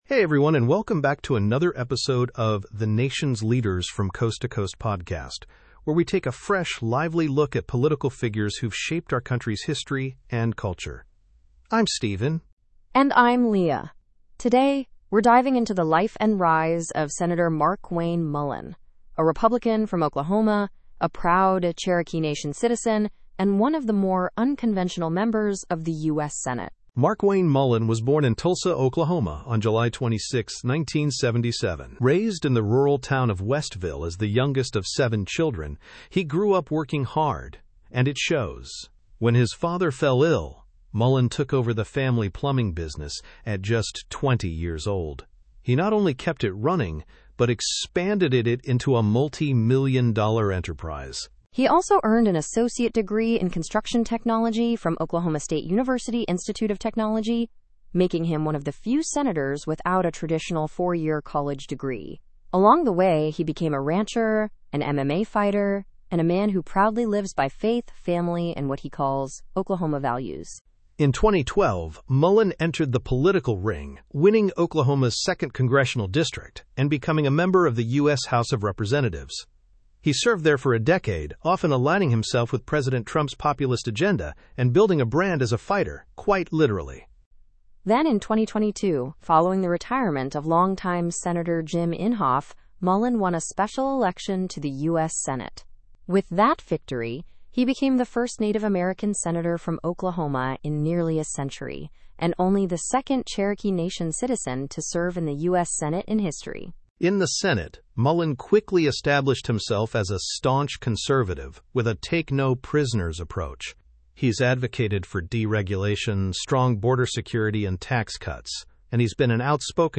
Tune in for a thoughtful, conversational look at one of today’s most colorful and controversial Republican senators.